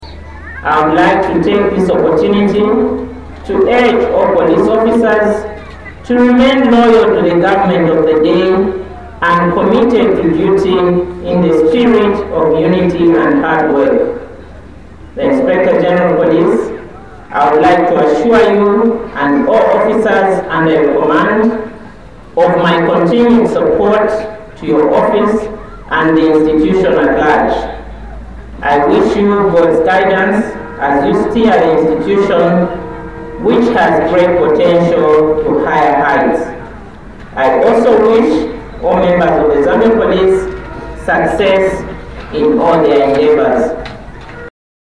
STELLA-LIBONGANI-URGING-POLLICE-OFFICERS-TO-REMAIN-LOYAL-TO-THE-GVT.mp3